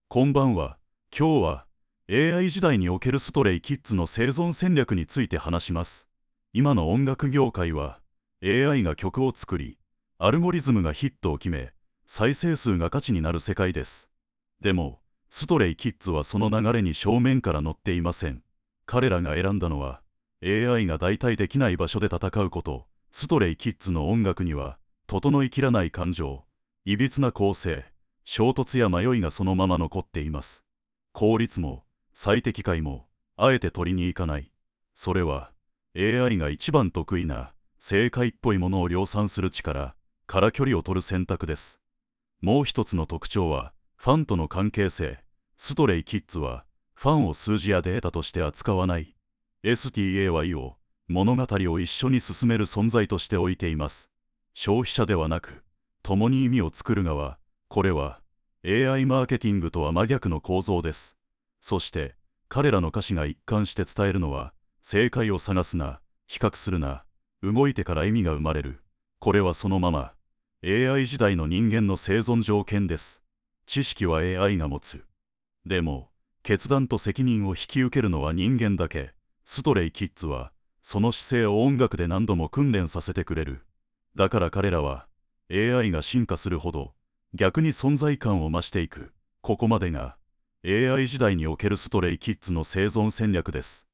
【AIラジオ】こんばんは。